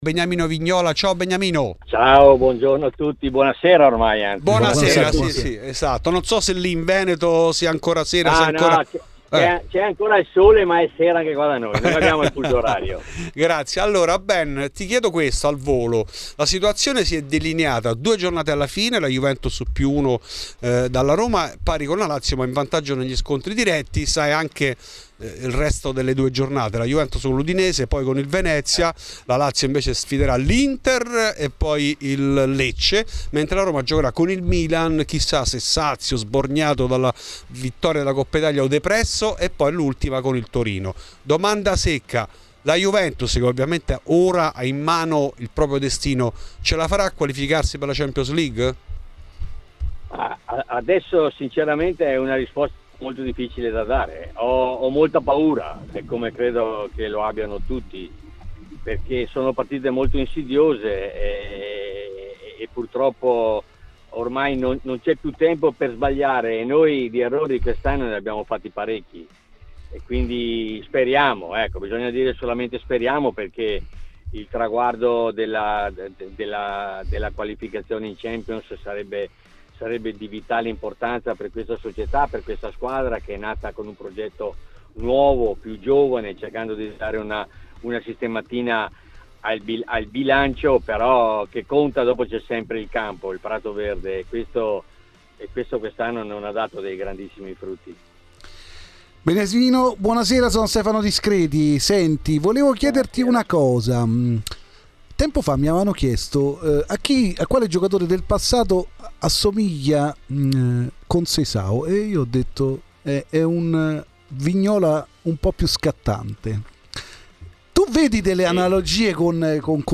Beniamino Vignola parla in ESCLUSIVA a Radio Bianconera, nel corso della trasmissione Fuori di Juve.